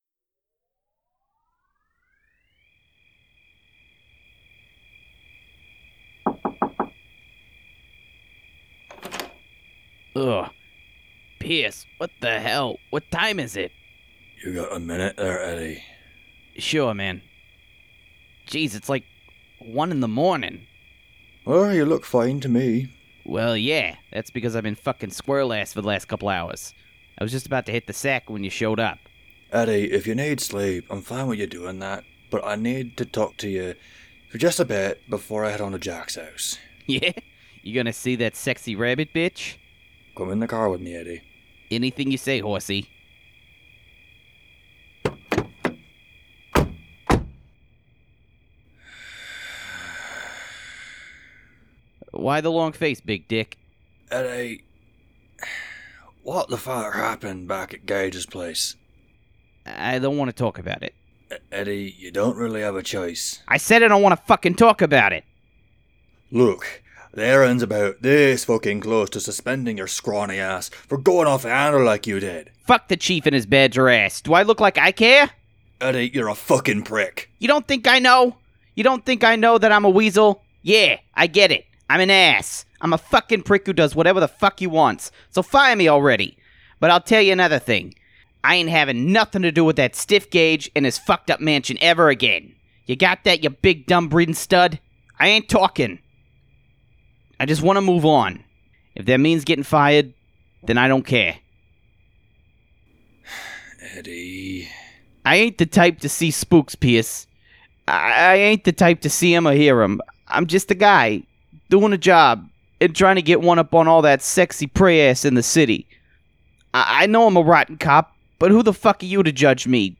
Audio Drama